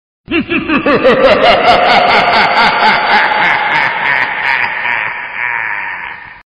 A Good Halloween Or Horror Themed Message Alert.